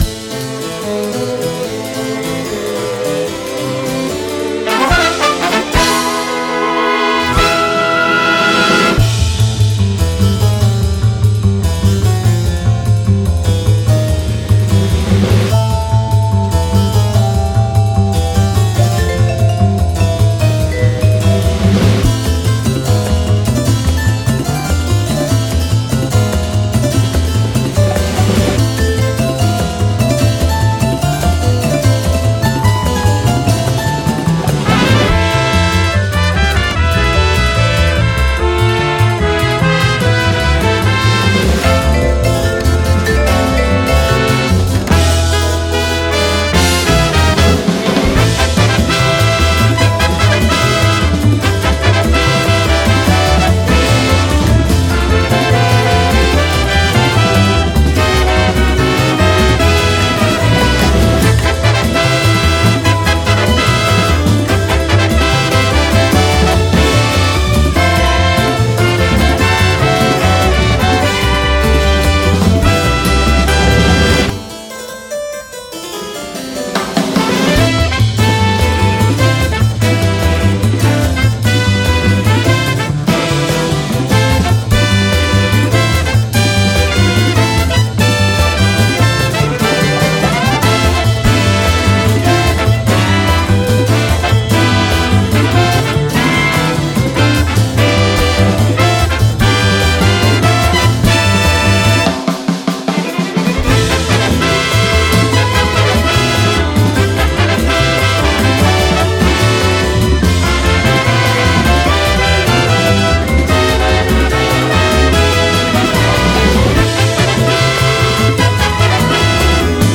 BPM147-294
Audio QualityMusic Cut
classy bebop title theme